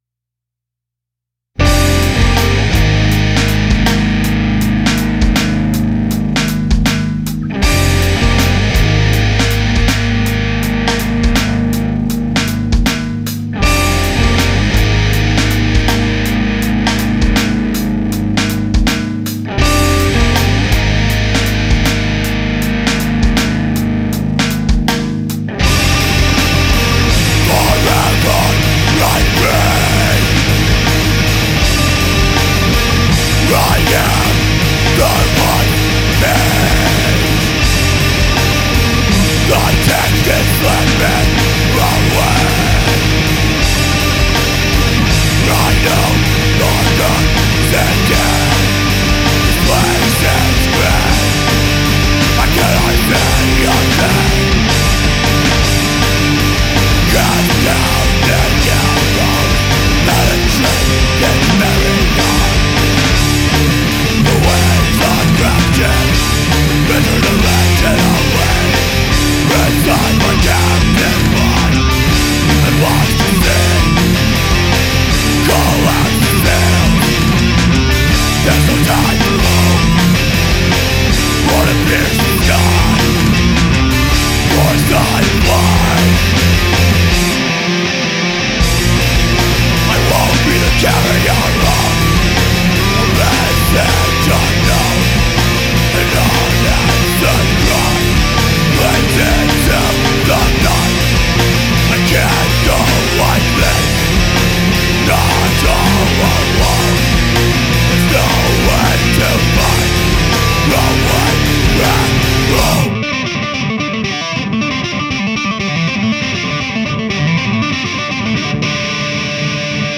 Here is one of my mixes with probably some of the worst equipment anyone could possible use, or at least the guitar amp was bad lol.